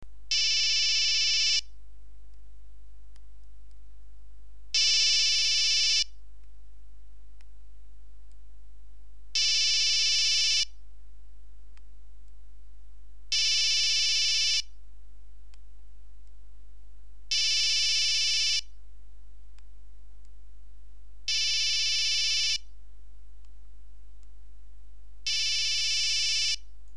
Звук Мелодия Низкий